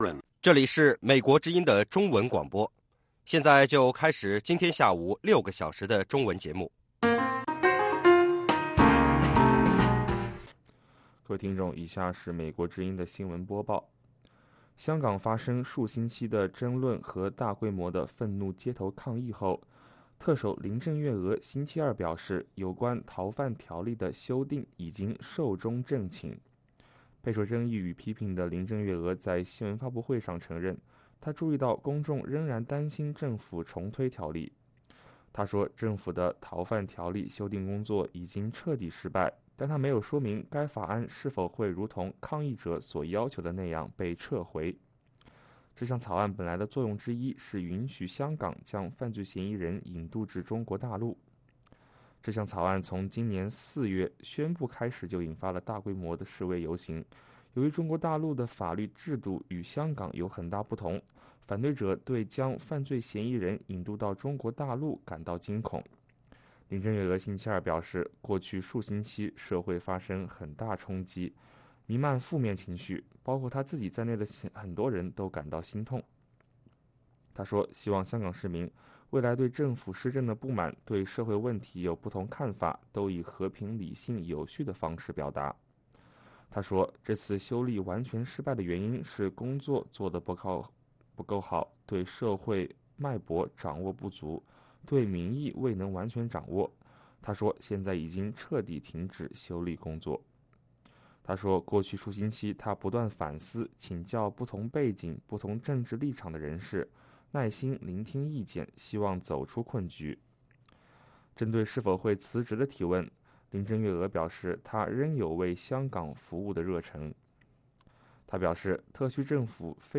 北京时间下午5-6点广播节目。